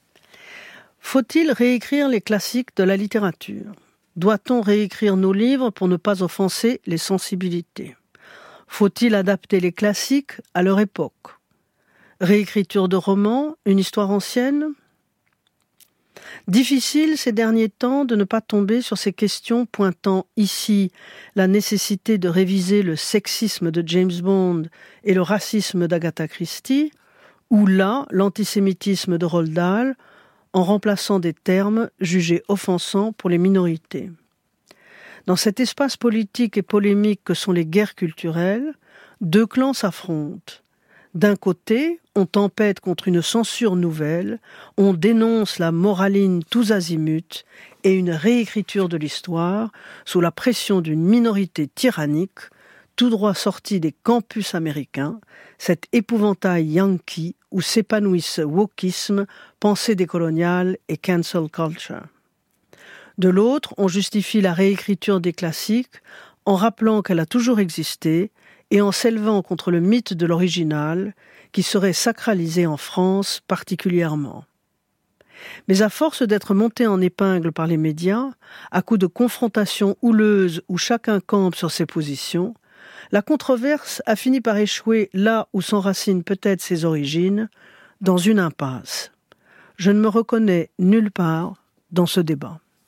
Premières lignes lues par l’autrice :